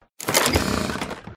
menu_opening.mp3